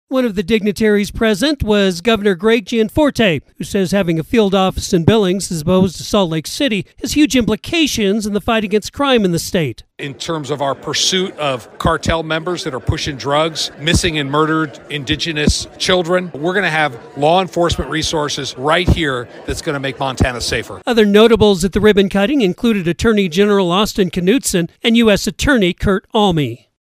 A ribbon cutting ceremony for the new FBI field office in Billings was held this afternoon.